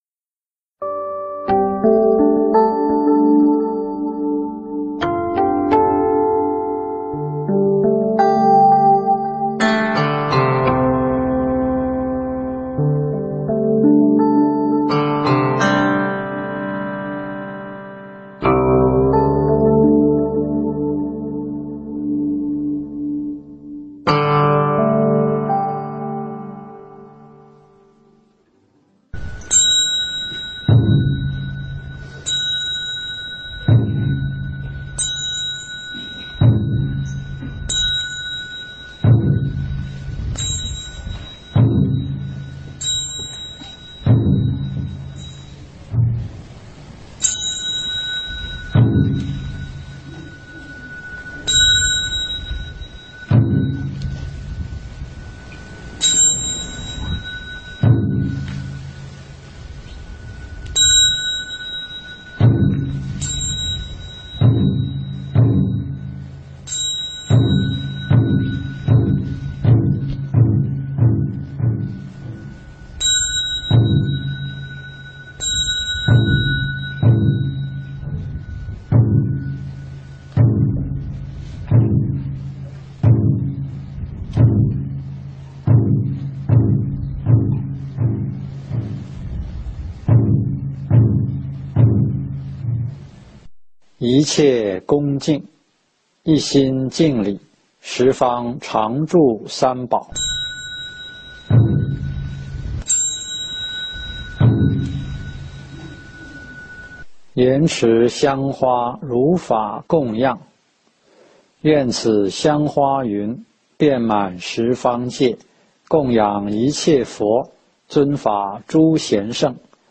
【占察懺】